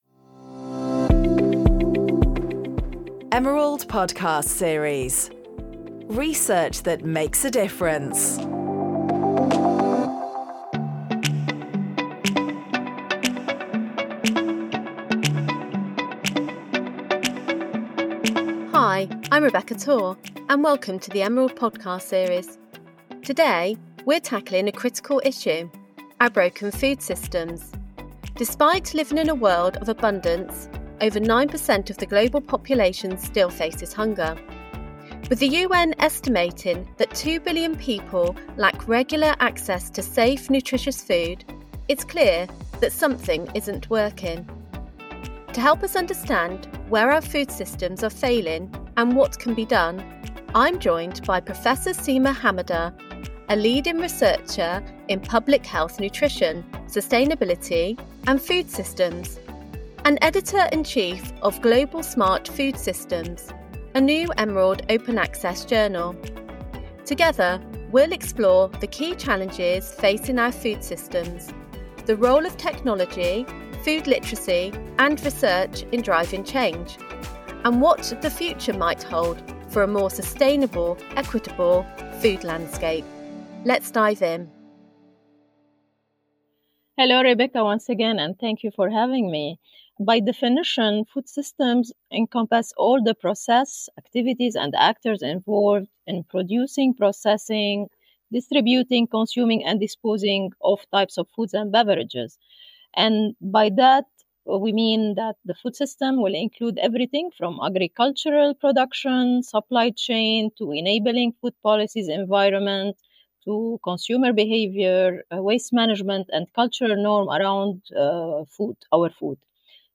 Conversation Across Generations Podcasts